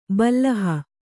♪ ballaha